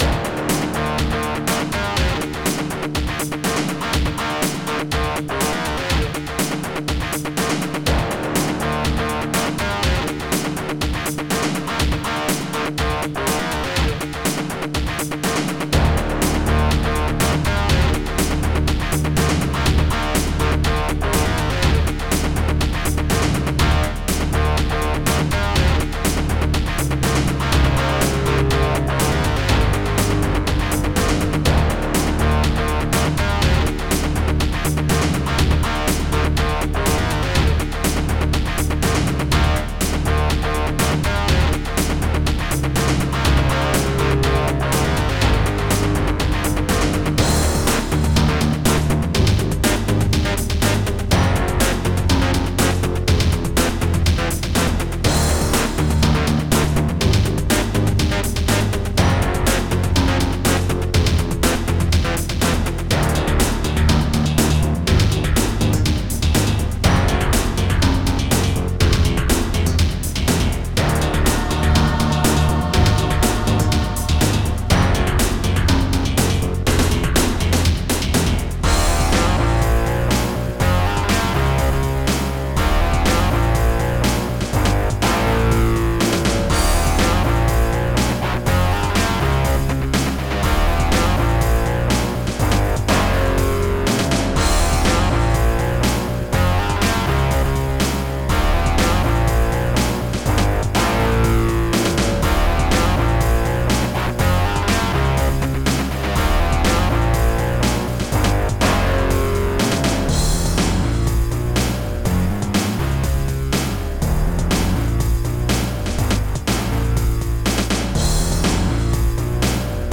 the music is a melding of electronic, techno, and rock.